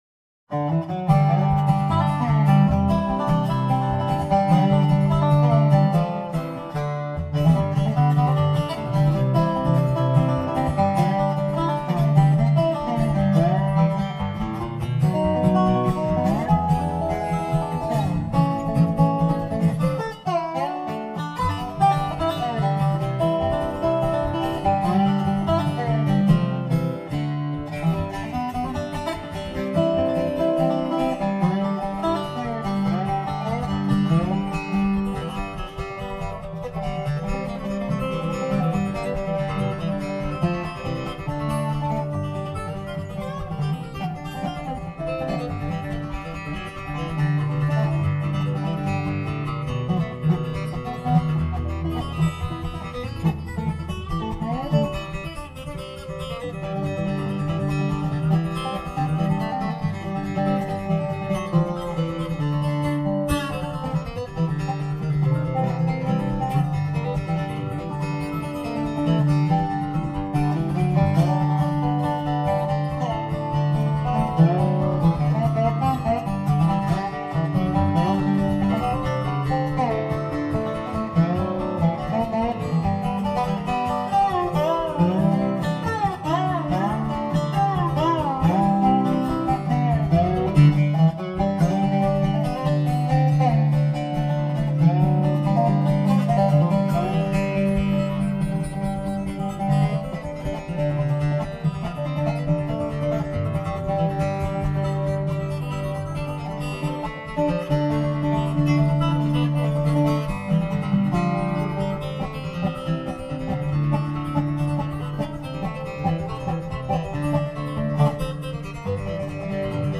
Dobro & Guitar